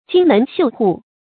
金门绣户 jīn mén xiù hù
金门绣户发音